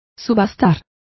Complete with pronunciation of the translation of auctioned.